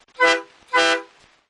Tesla Lock Sound Horn – Train
Horn Train sound
(This is a lofi preview version. The downloadable version will be in full quality)
JM_Tesla_Lock-Sound_Horn-Train_Watermark.mp3